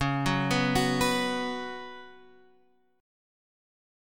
Db7sus4 Chord